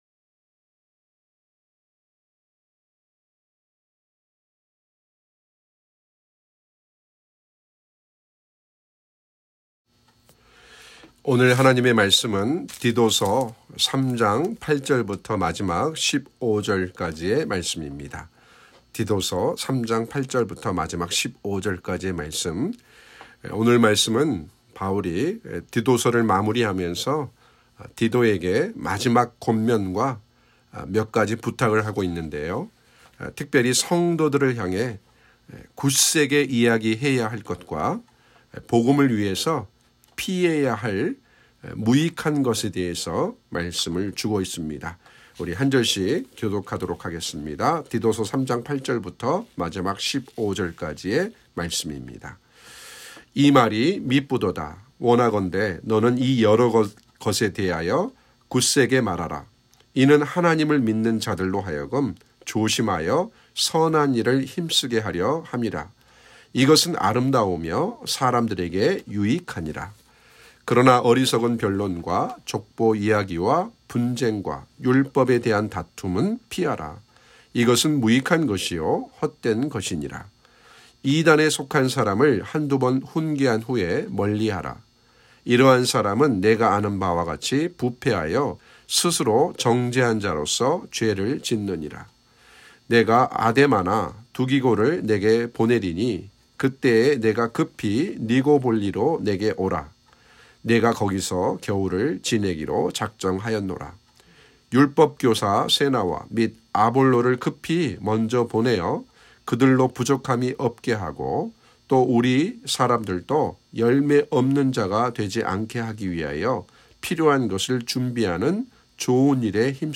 7월 9일 주일 예배 준비 중입니다. 7월 8일 토요 새벽 예배입니다. 7월 7일 금요 새벽 예배입니다. 7월 6일 목요 새벽 예배입니다. 7월 5일 수요 새벽 예배입니다.